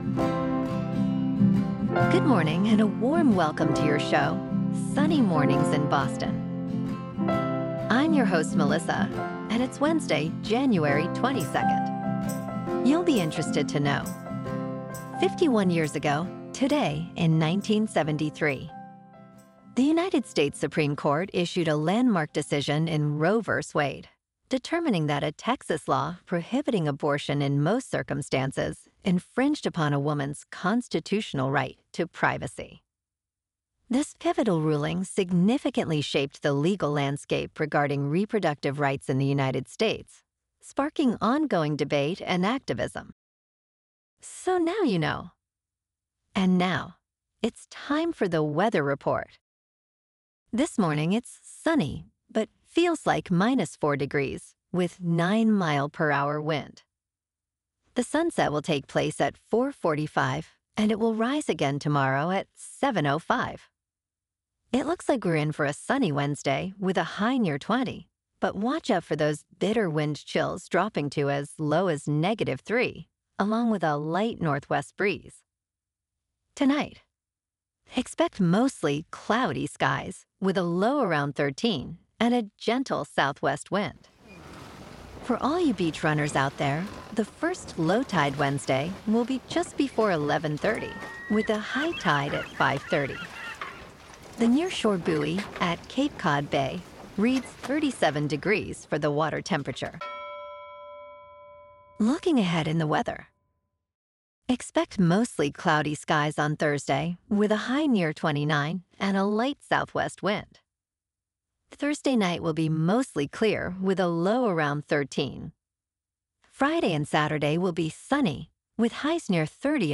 Your "Hyper-Local" 12 Minute Daily Newscast with: